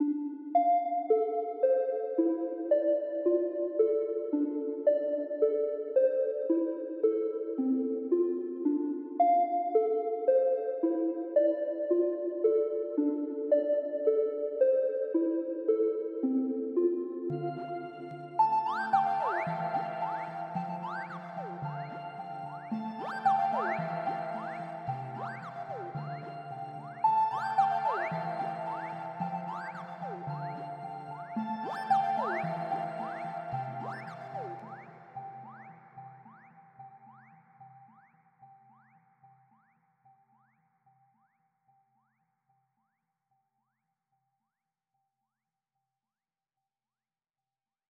saikik melo loop løslatt.wav